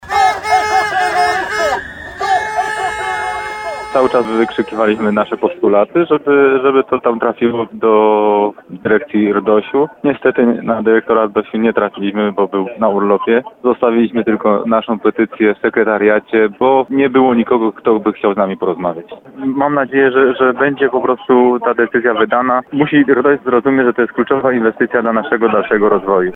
– mówił Andrzej Źrołka, radny gminy Muszyna.